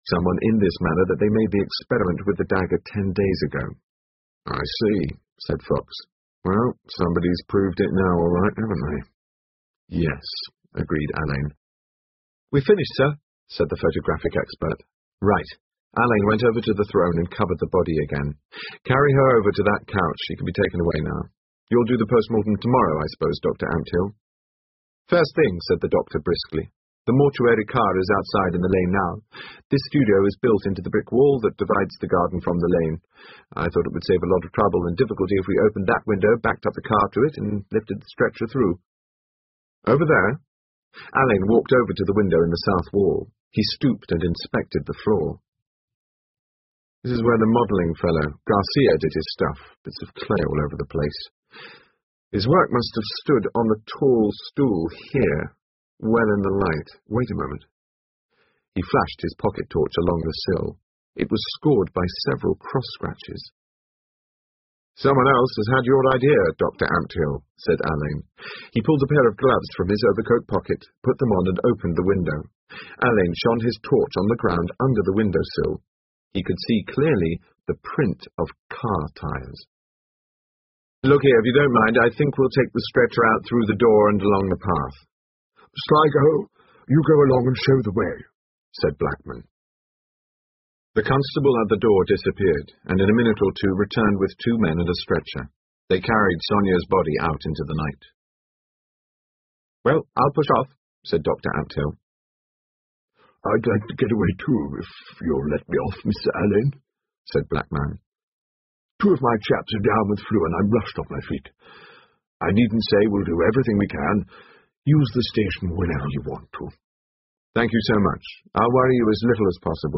英文广播剧在线听 Artists in Crime 10 听力文件下载—在线英语听力室